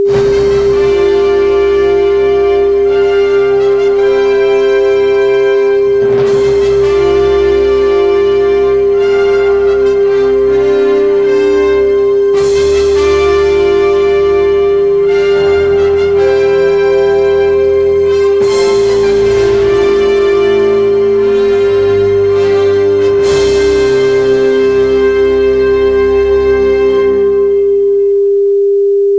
audio sample with 400 Hz hum
hum_testsound.wav